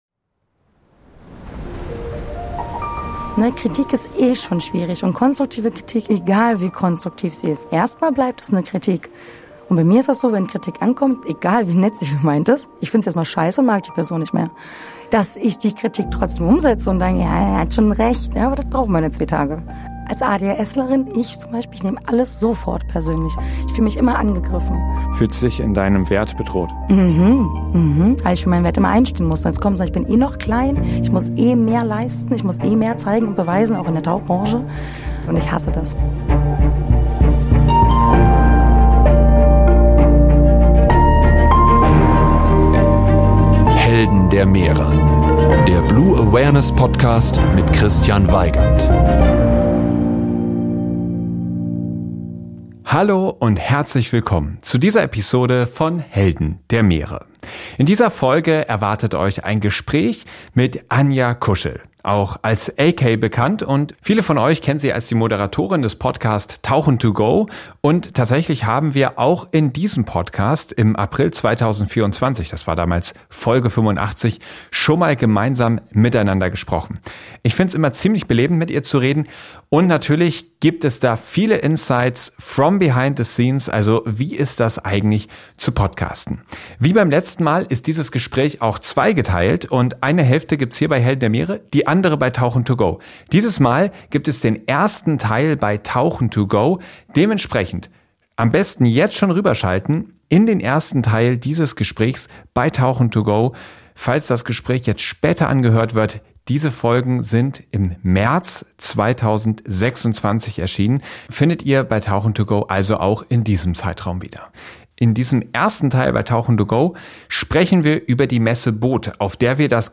Es wurde ein sehr persönliches Gespräch, bei dem ihr nicht nur ein paar „Behind the Szenes“ bekommt, sondern wir auch darüber sprechen, was uns in unseren Leben geprägt und zu den Moderatoren gemacht hat, die wir heute sind.